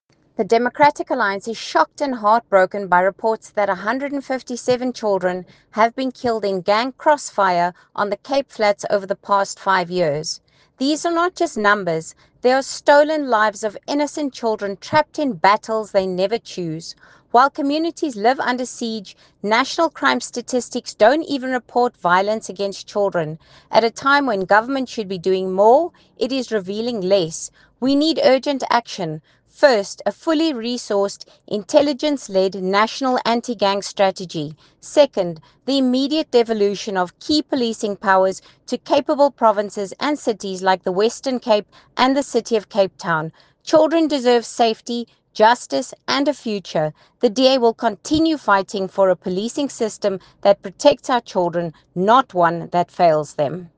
Afrikaans soundbite by Lisa Schickerling MP.